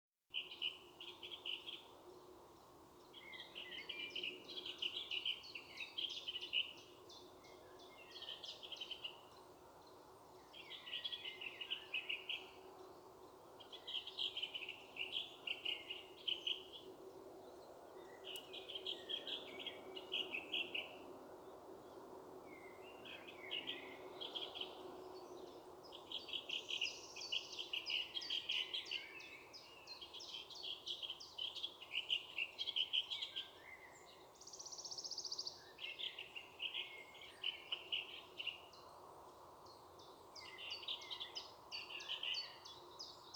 Птицы -> Дроздовые ->
рябинник, Turdus pilaris
СтатусПоёт